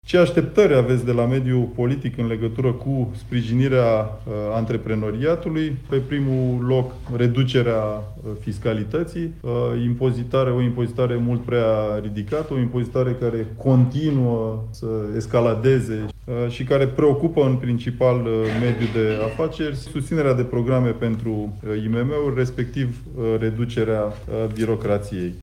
Președintele IMM România, Florin Jianu: „Pe primul loc: reducerea fiscalității”